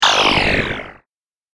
機械・乗り物 （94件）
ショット10.mp3